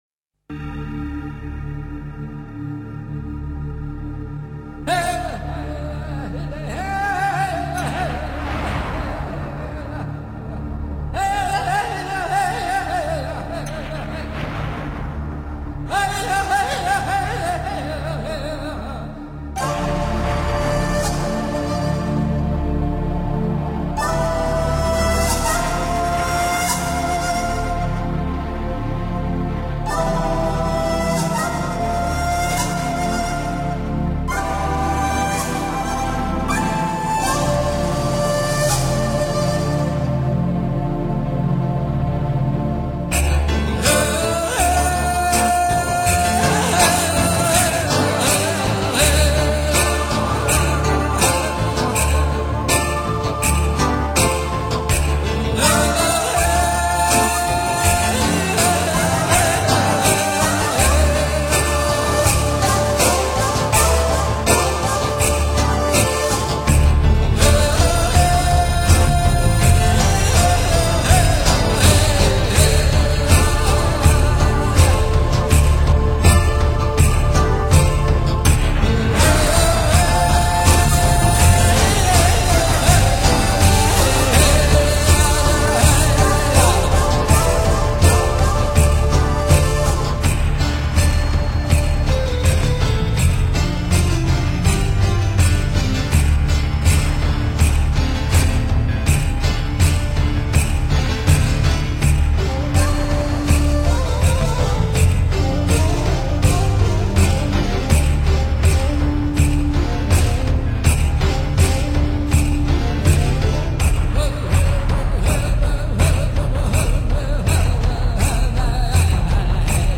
专辑类别：部落民间
土长笛和现代人文的质感组成的乐章集中一体。
本土鼓演奏出的心跳节奏，组成了基础乐章，